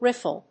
発音記号
• / rífl(米国英語)